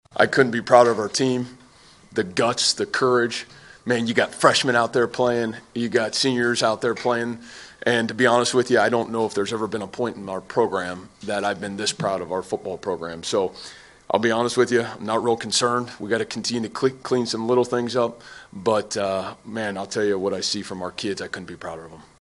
That’s Cyclone coach Matt Campbell who says the bye week will help them get healthier.